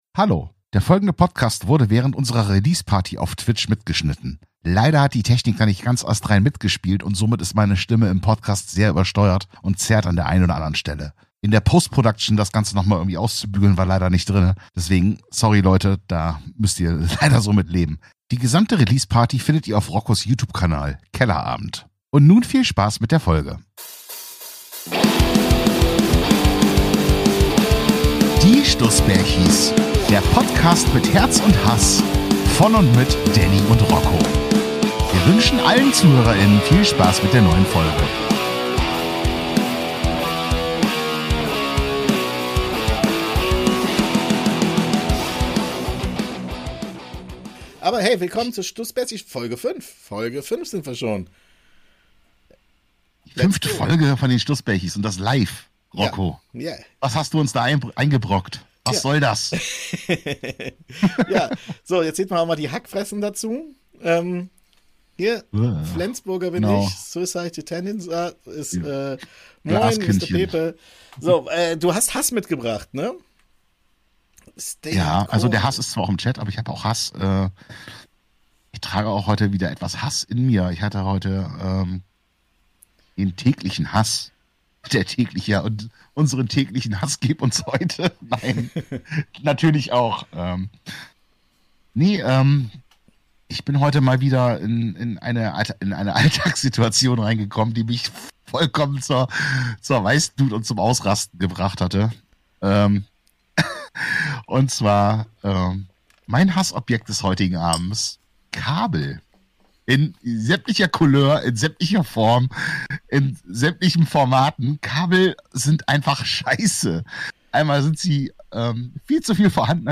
Live von der Kellerabend Release Party!